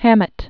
(hămĭt), Dashiell 1894-1961.